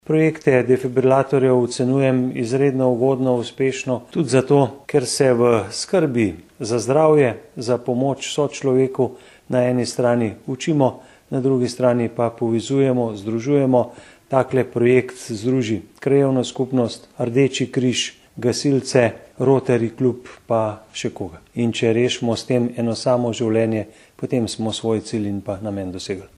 izjavazupanaobcinetrzicmag.borutasajovicaonovihdefibrilatorjih.mp3 (639kB)